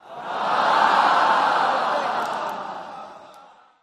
Crowd Ahh Reaction Effect